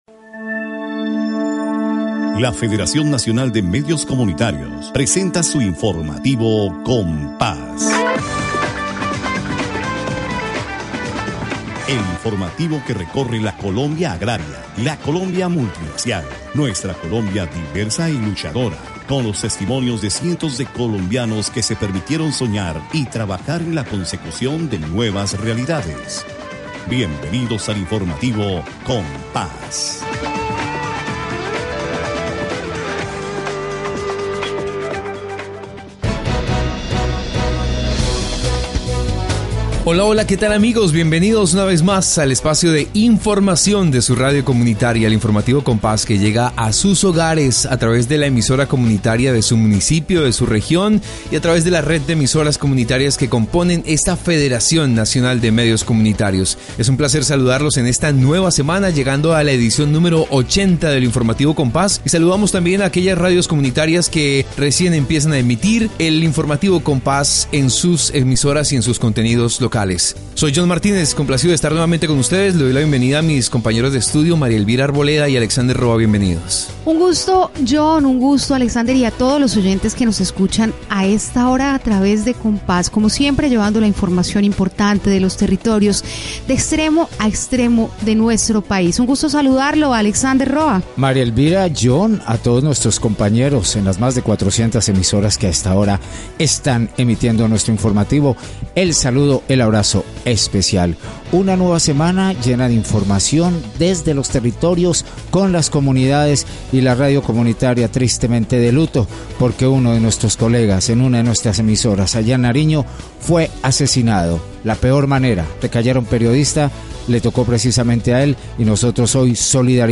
La radio comunitaria de Providencia y Santa Catalina presenta un reportaje sobre las festividades locales de estas islas del Caribe colombiano, la versión N°46 del Festival Folclórico Cultural y Deportivo denominado «Back to our Roots»